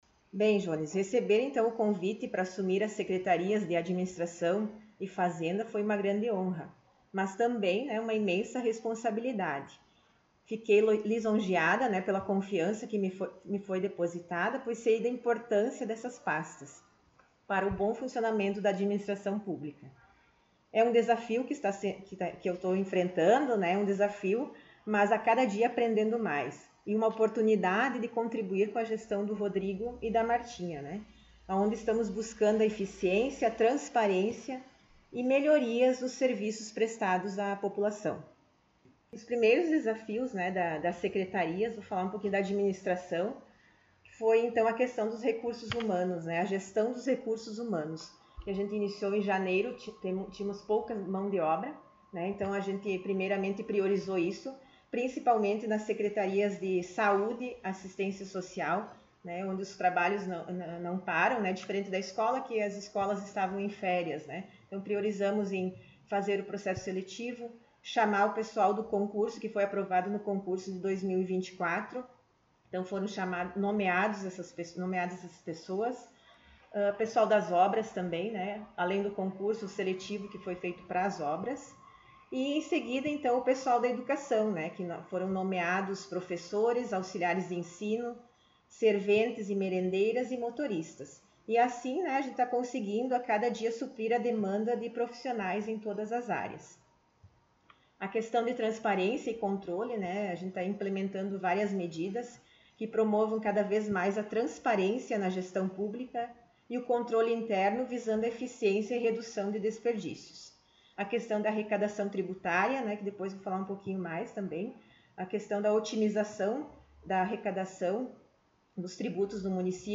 Secretária Municipal de Administração e Fazenda concedeu entrevista
O Colorado em Foco esteve na Prefeitura, na sala da secretária, para sabermos um pouco mais da situação econômica do município e outros assuntos.